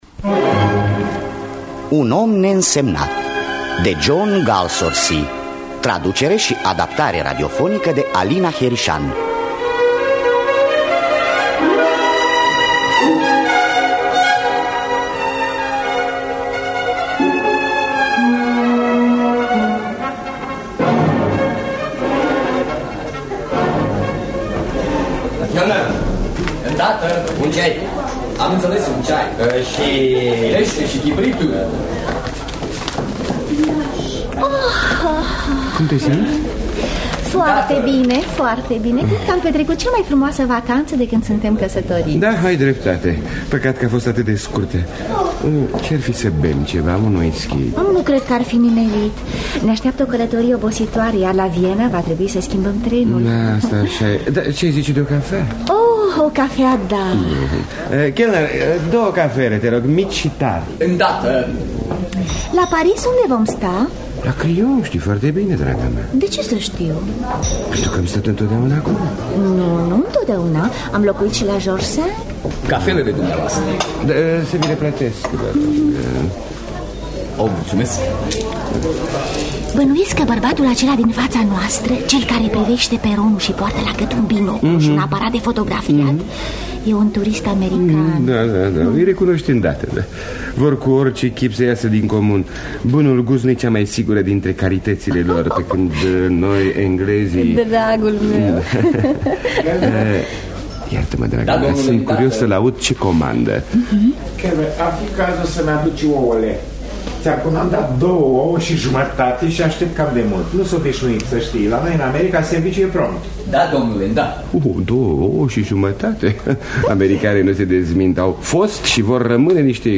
Traducerea şi adaptarea radiofonică